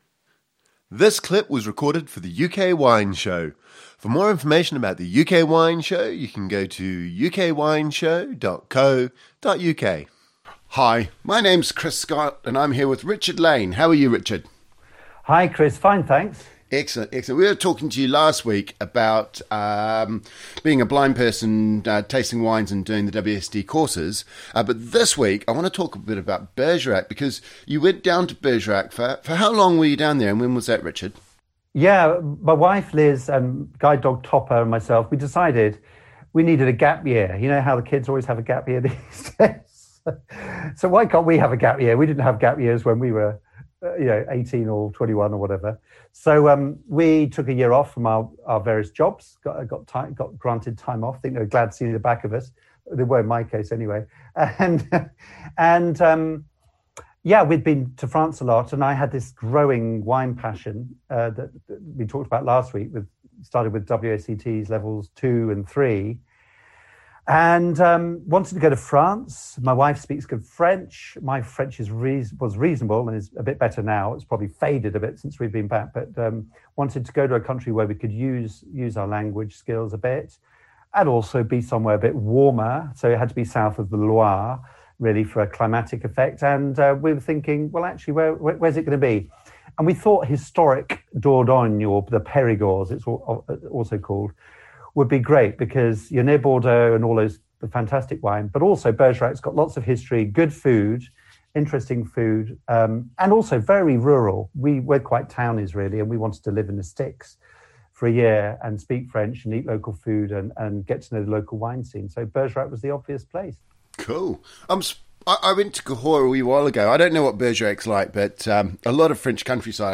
Note: this interview was recorded 2-3 weeks before Lockdown 2 in England.